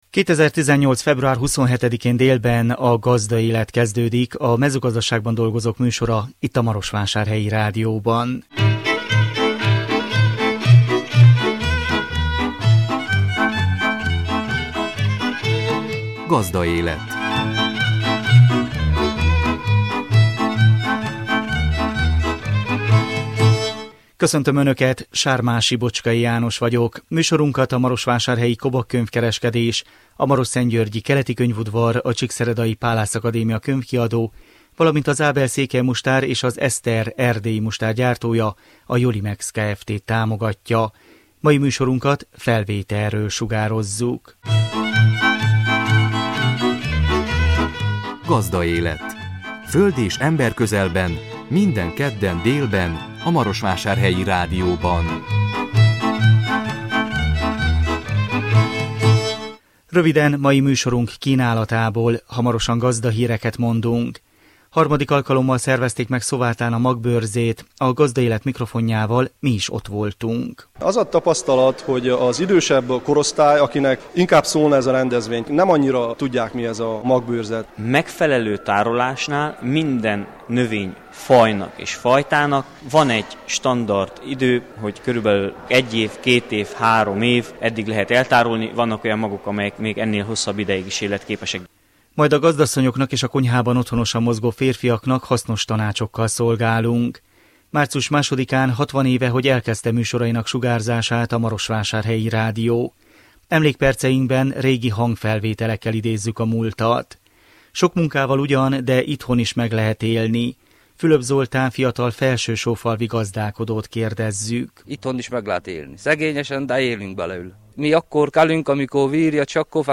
A Gazdaélet mikrofonjával mi is ott voltunk. Majd a gazdasszonyoknak és a konyhában otthonosan mozgó férfiaknak hasznos tanácsokkal szolgálunk.
Emlékperceinkben régi hangfelvételekkel idézzük a múltat.